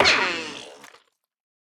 Minecraft Version Minecraft Version snapshot Latest Release | Latest Snapshot snapshot / assets / minecraft / sounds / mob / armadillo / death1.ogg Compare With Compare With Latest Release | Latest Snapshot